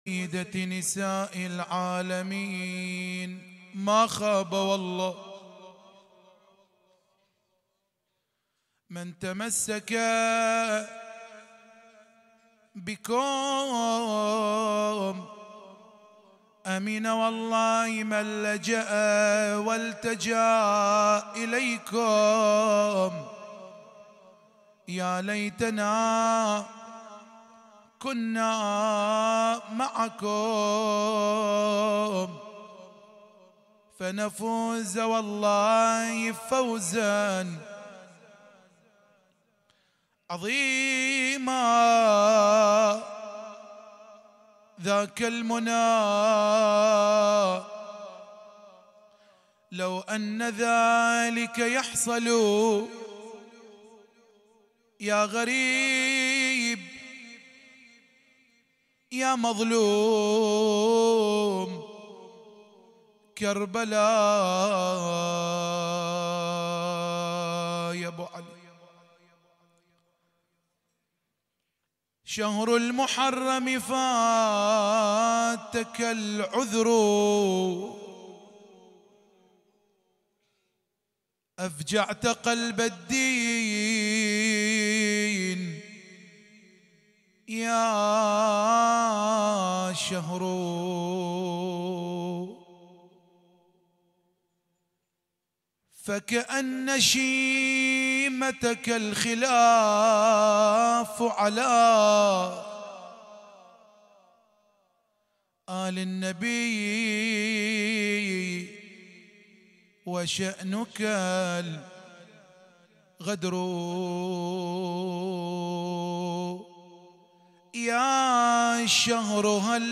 مجلس العزاء